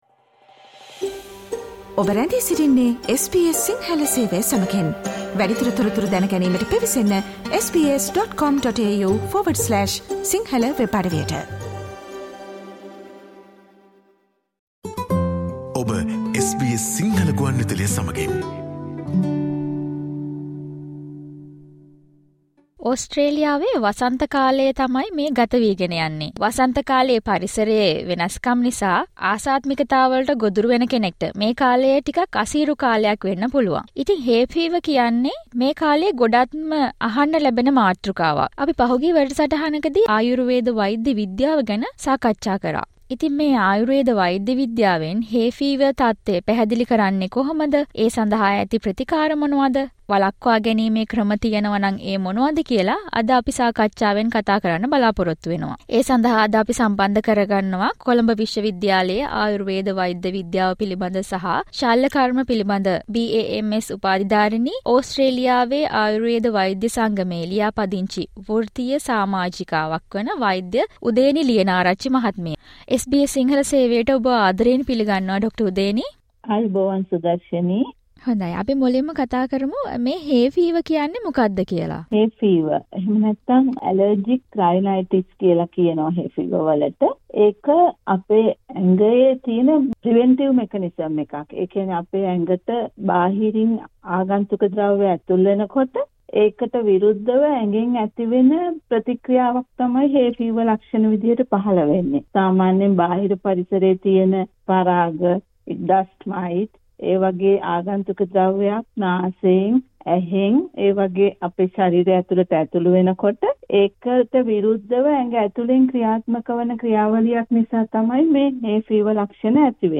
Even though we live in a western country, one may want to know about the treatments/solutions offered by Ayurvedic medicine for allergies such as hay fever. Listen to SBS Sinhala discussion on this topic